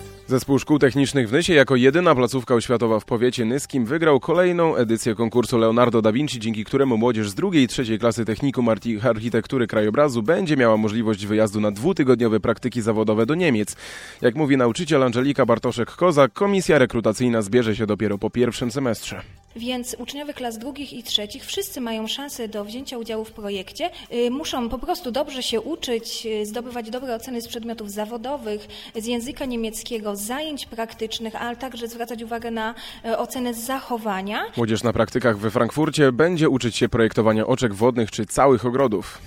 20-25 września 2013 r. – emisja na antenie Radio Nysa FM informacji nt. realizacji projektu Leonardo da Vinci przez Zespół Szkół Technicznych w Nysie